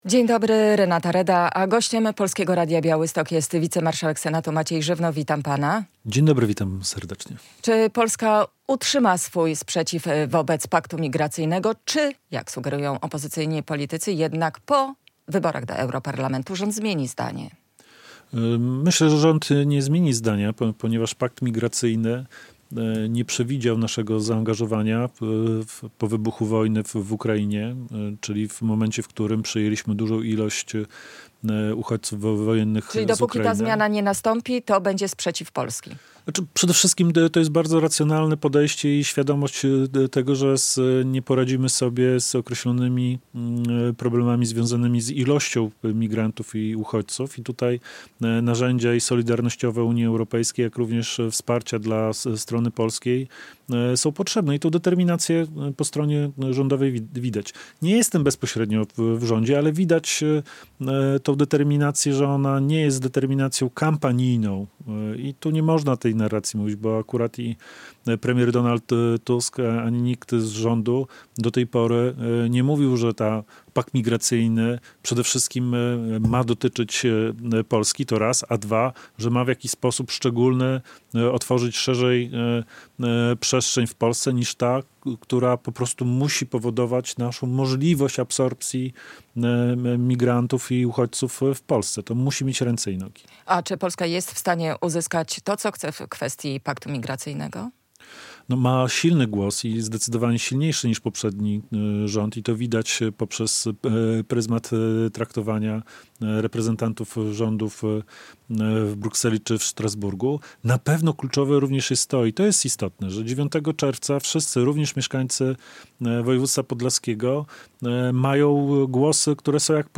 wicemarszałek Senatu
Fragment rozmowy: